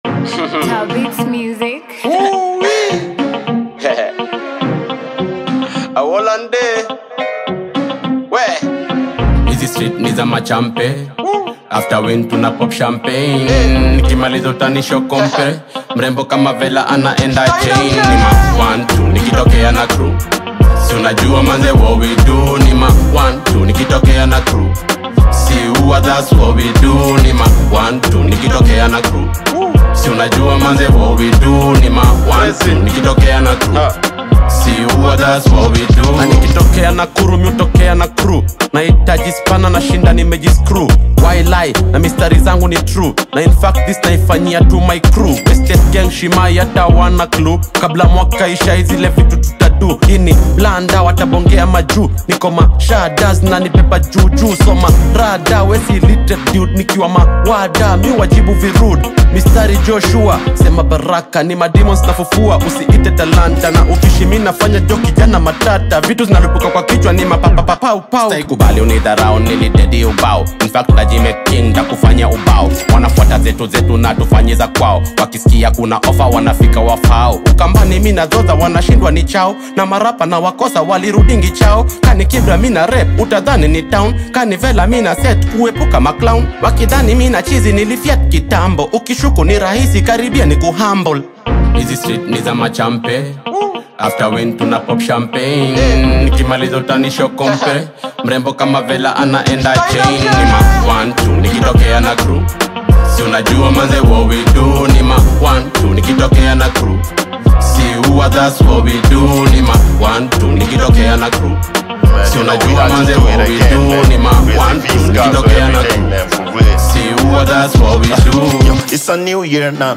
Kenya’s hip-hop scene just got hotter!
energetic and punchy style